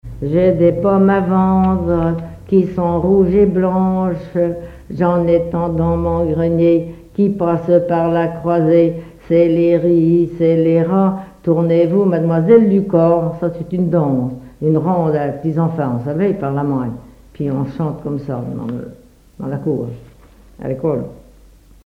rondes enfantines (autres)
collecte en Vendée
répertoire enfantin
Pièce musicale inédite